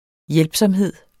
Udtale [ ˈjεlbsʌmˌheðˀ ]